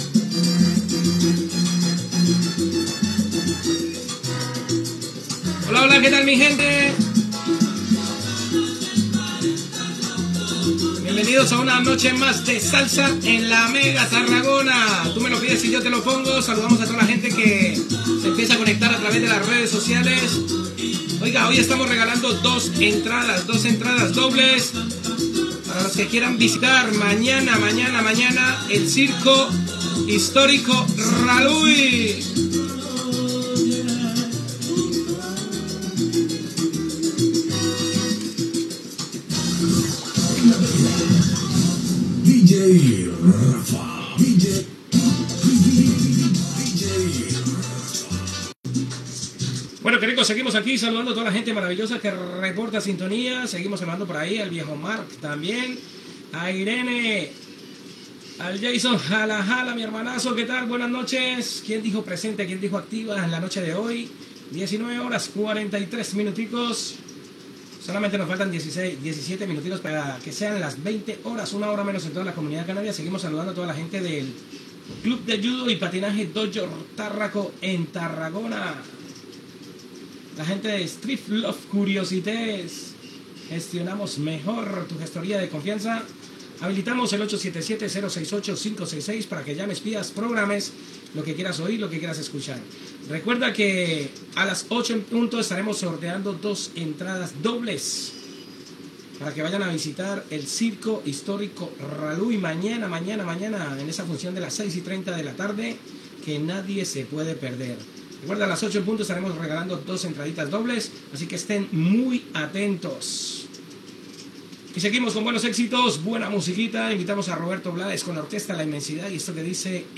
Llatina
Identificació de l'emissora, salutacions, sorteig d'entrades per al circ Raluy, indicatiu del presentador, hora, salutacions, telèfon, sorteig d'entrades i tema musical